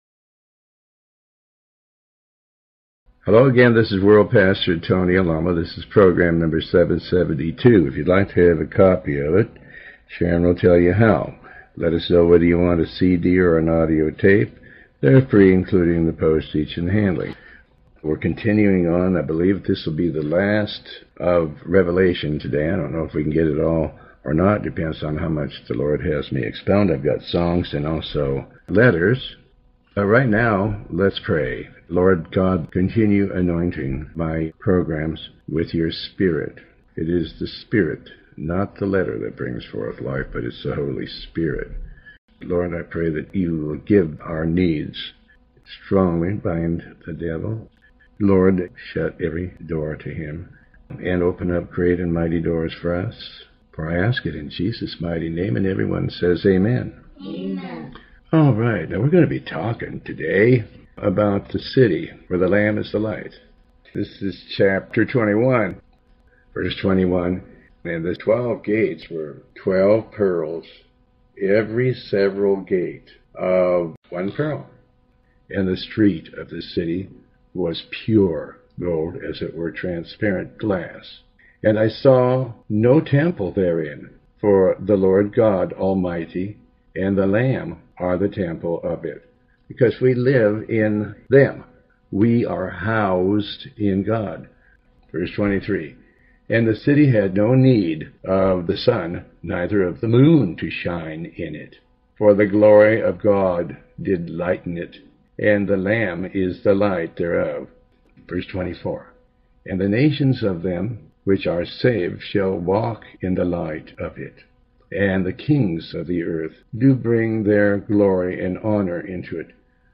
Pastor Tony Alamo reads and comments on chapters 21 and 22 of the Book of Revelation. This program originally aired in July of 2008.